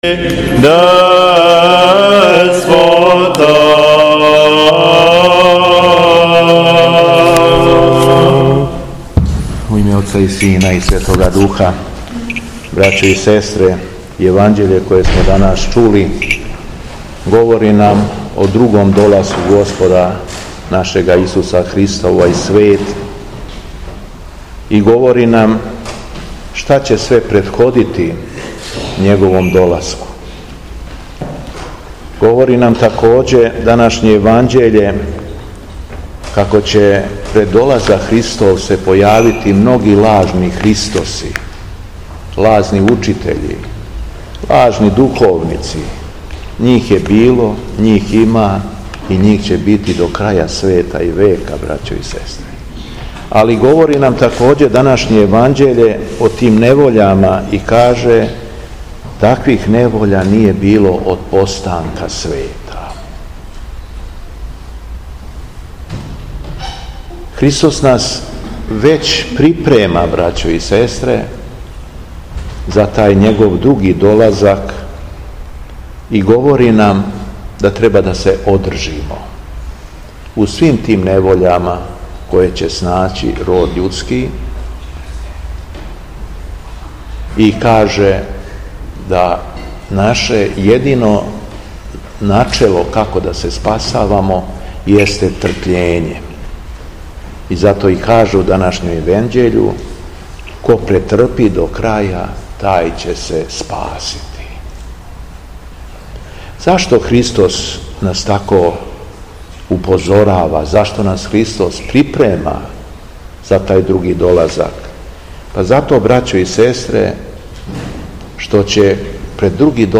Беседа Његовог Преосвештенства Епископа шумадијског г. Јована
Након прочитаног јеванђелског зачала Епископ се богонадахнутом беседом обратио окупљеним верницима, рекавши: